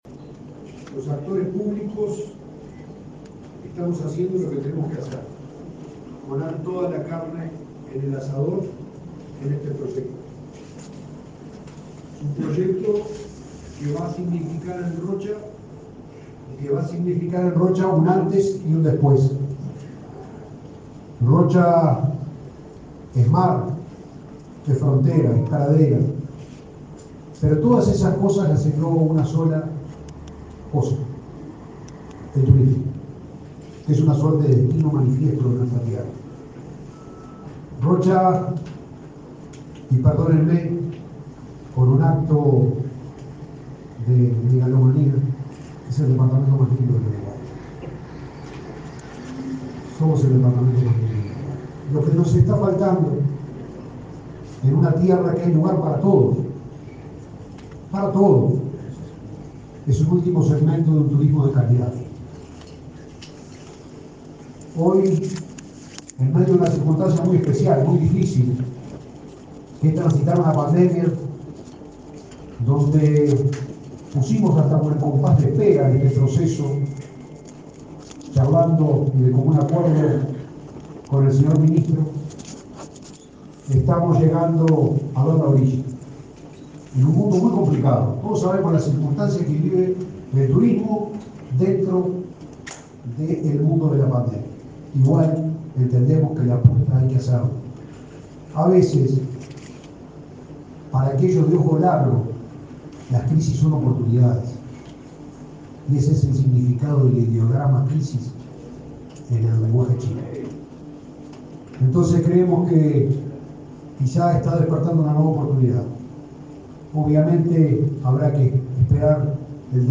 Declaraciones del intendente de Rocha, Alejo Umpiérrez, en el lanzamiento del llamado para la construcción de un hotel 5 estrellas y casino
El llamado involucra la costa oceánica de Rocha, entre La Paloma y Chuy, según se anunció en la conferencia de prensa realizada este 10 de febrero en